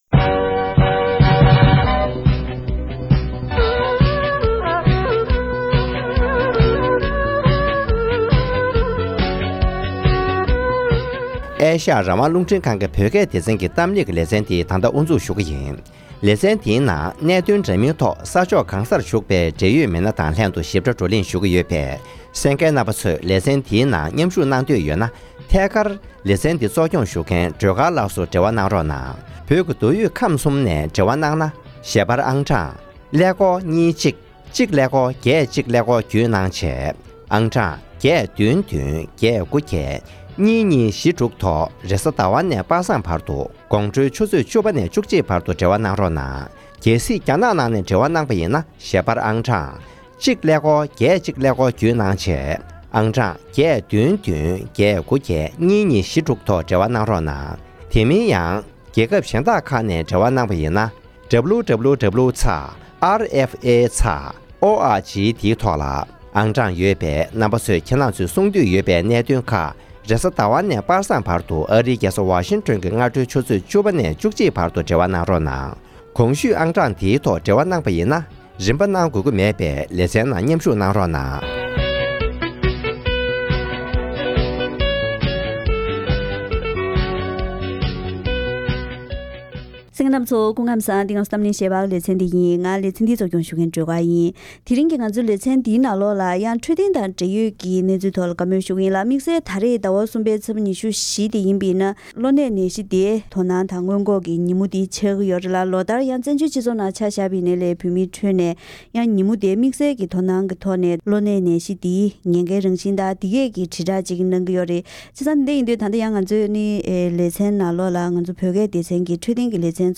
༄༅། །ཐེངས་འདིའི་གཏམ་གླེང་ལེ་ཚན་ནང་། བོད་ཕྱི་ནང་གཉིས་སུ་ཡོད་པའི་བོད་པའི་སྤྱི་ཚོགས་ནང་གློ་ནད་TBའམ་ཡང་ན་གློ་གཅོང་གི་ནད་གཞི་འདི་ཁྱབ་གདལ་ཆེན་པོ་ཡོད་པར་དམིགས་ནས་ནད་གཞི་འདིའི་བགོས་སྟངས་དང་སྔོན་འགོག ཉེན་ཁའི་རང་བཞིན་སོགས་ཀྱི་ཐད་སྨན་པ་དང་ལྷན་བཀའ་མོལ་ཞུས་པ་ཞིག་གསན་རོགས་གནང་།།